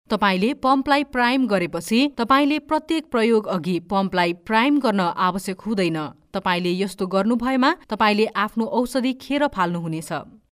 Nepali voice overs talent.
Nepali female voiceovers   Nepali male voice talents